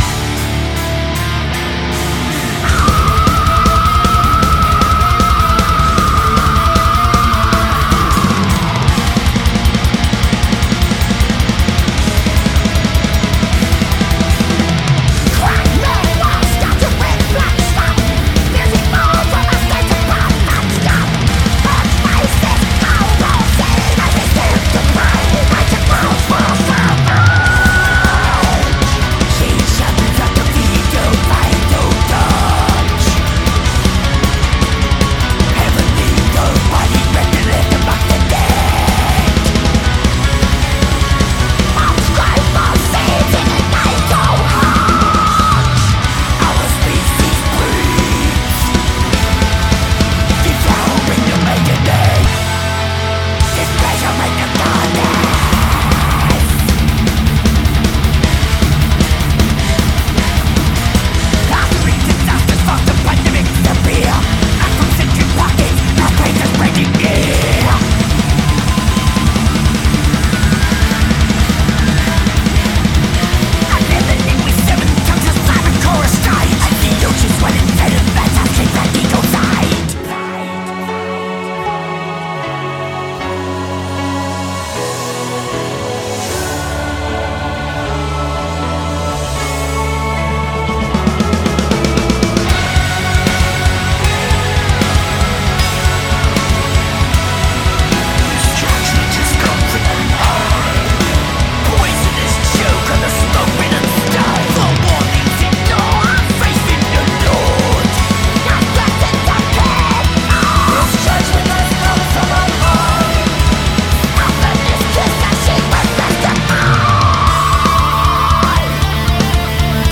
BPM68-310
Audio QualityPerfect (High Quality)
Comments[EXTREME GOTHIC METAL]